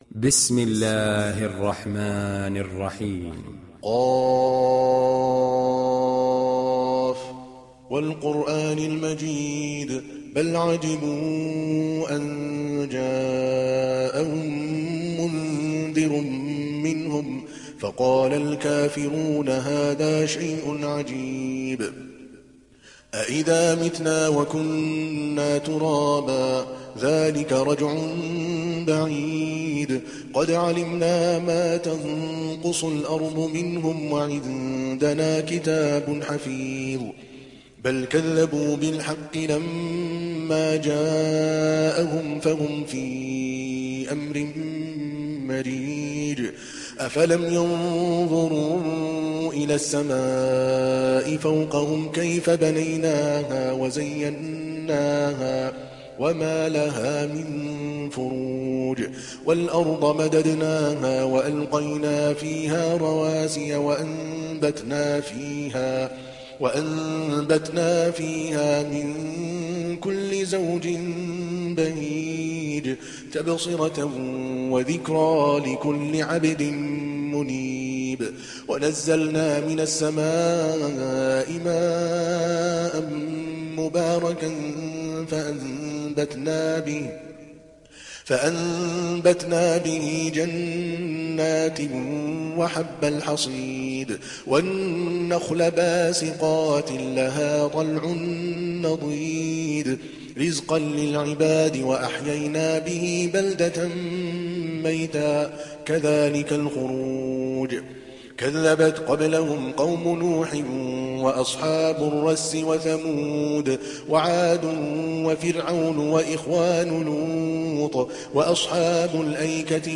Surat Qaf mp3 Download Adel Al Kalbani (Riwayat Hafs)